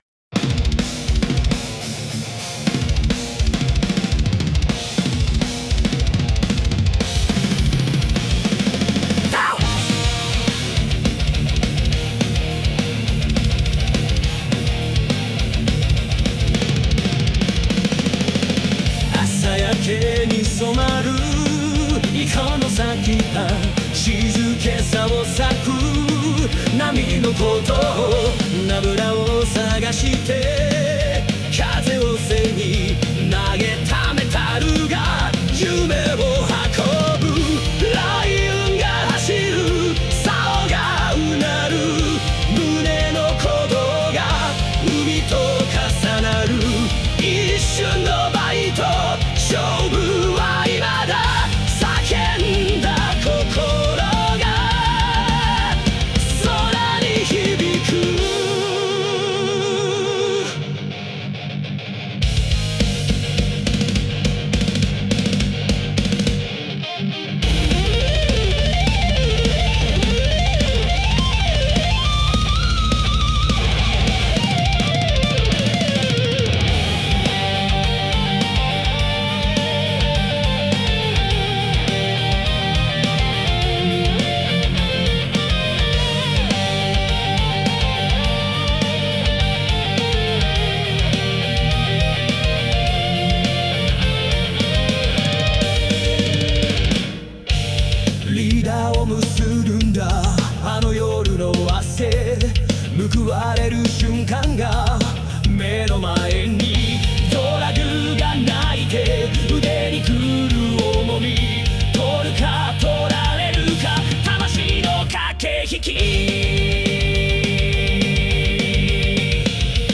『青物一閃（アオモノイッセン）』♪紀州青物釣りの歌♪メタル風アレンジ！